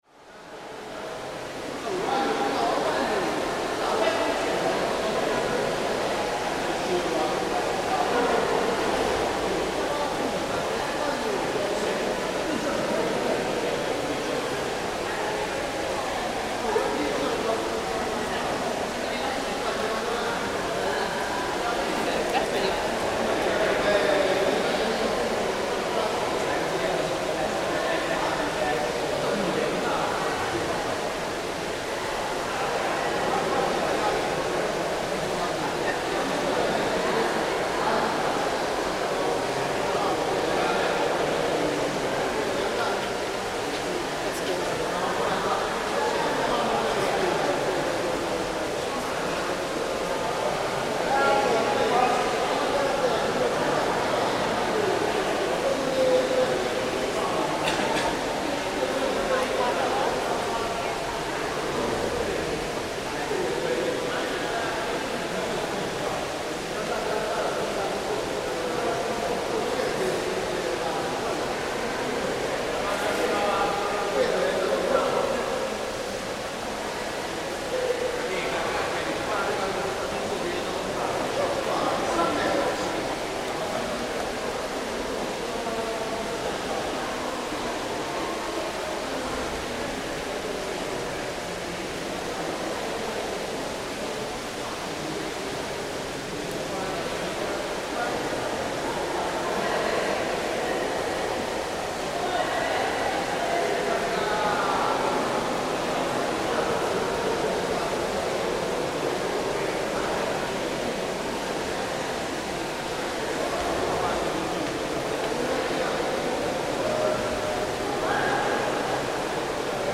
Ornate swimming pool, Gellert Baths
This is a recording from inside the large, ornate swimming pool room." Recorded by Cities and Memory in Budapest, Hungary.